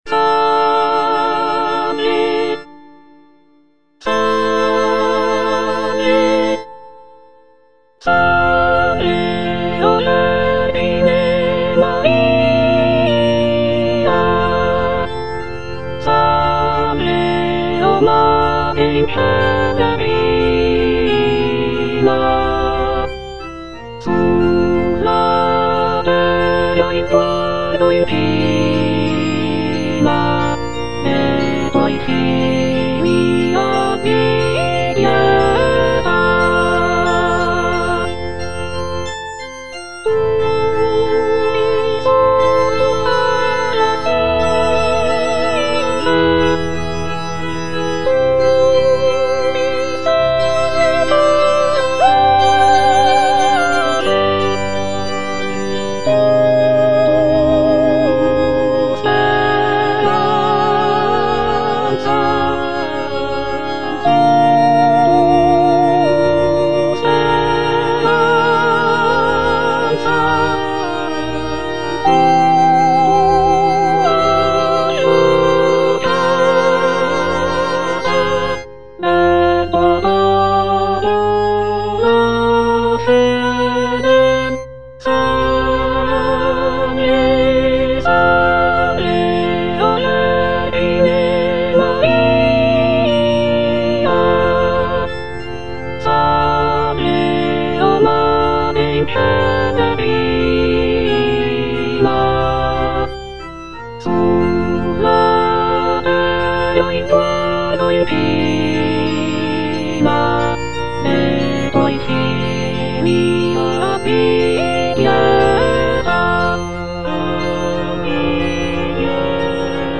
G. ROSSINI - SALVE O VERGINE MARIA Soprano (Emphasised voice and other voices) Ads stop: auto-stop
"Salve o vergine Maria" is a choral piece composed by Gioachino Rossini in 1831. It is a prayer to the Virgin Mary, and it is often performed during religious ceremonies. The music is characterized by its serene and devotional atmosphere, with lush harmonies and expressive melodies.